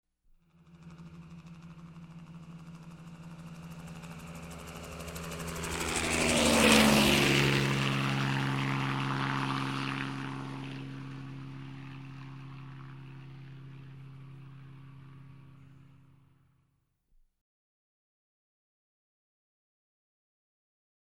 Звуки вертолёта
3D звук пролетающего вертолета